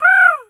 crow_raven_call_squawk_02.wav